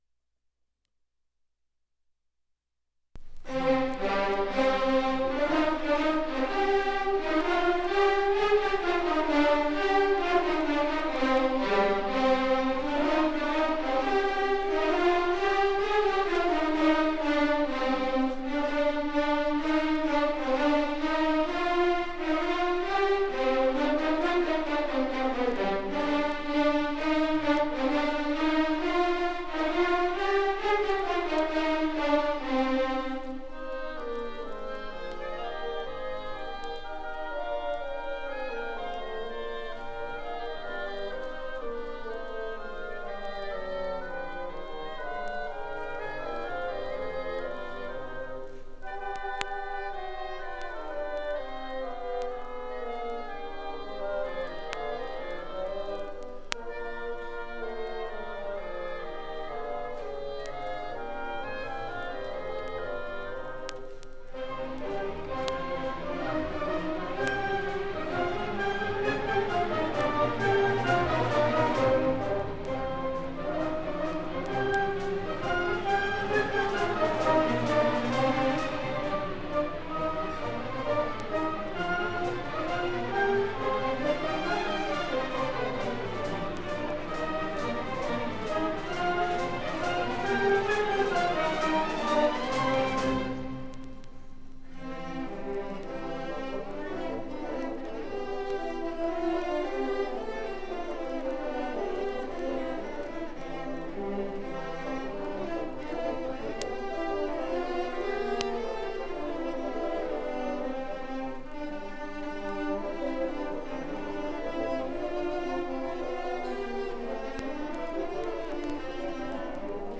1984 Music in May orchestra and choir performance recording · Digital Exhibits · heritage
6aaac35e6f8c7706ac6b618216c1f8fa777994af.mp3 Title 1984 Music in May orchestra and choir performance recording Description An audio recording of the 1984 Music in May orchestra and choir performance at Pacific University. Music in May is an annual festival that has been held at Pacific University since 1948. It brings outstanding high school music students together on the university campus for several days of lessons and events, culminating in the final concert that this recording preserves.